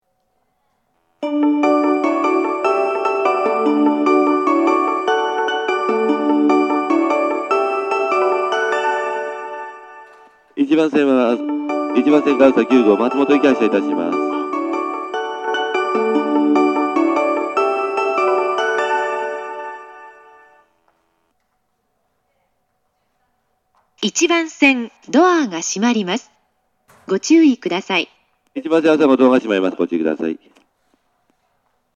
発車メロディー
2コーラスです。